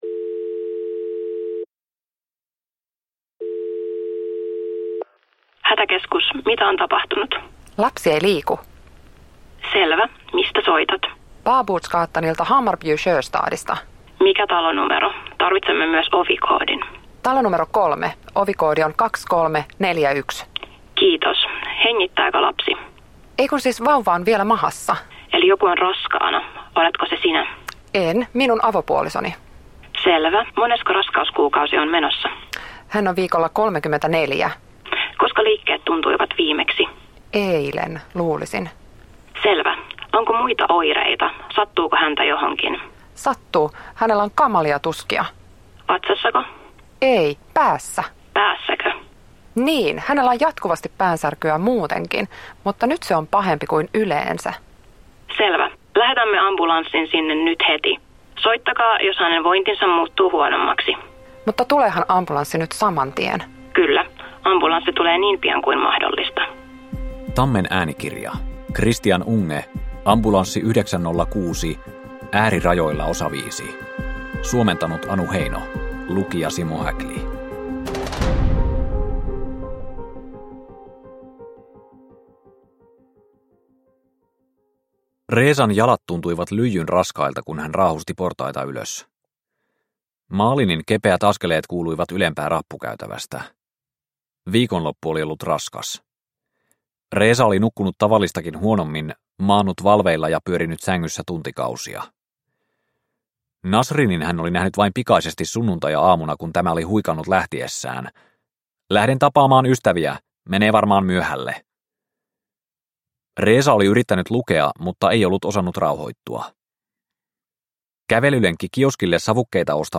Ambulanssi 906 Osa 5 – Ljudbok – Laddas ner